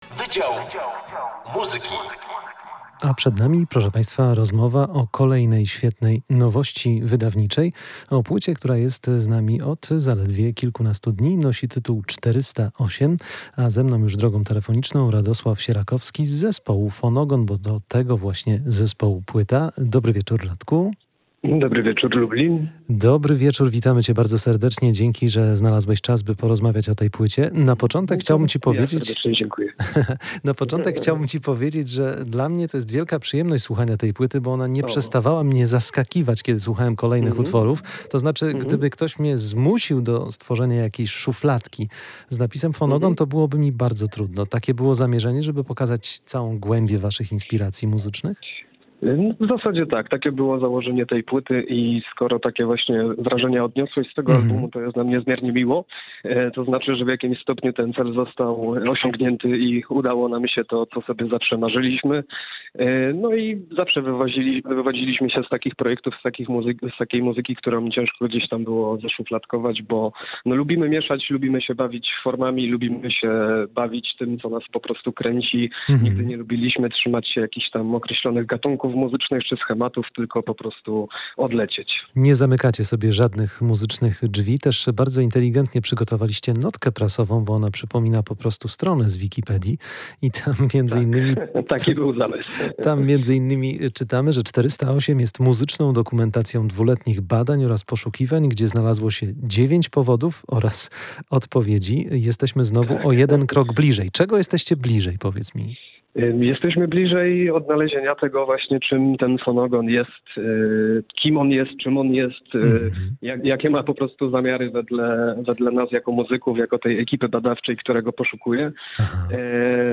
Wydział Muzyki: Fonogon, czyli jak unikać muzycznych szufladek [POSŁUCHAJ ROZMOWY]